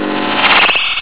autocomplete.ogg